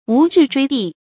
無置錐地 注音： ㄨˊ ㄓㄧˋ ㄓㄨㄟ ㄉㄧˋ 讀音讀法： 意思解釋： 見「無立錐之地」。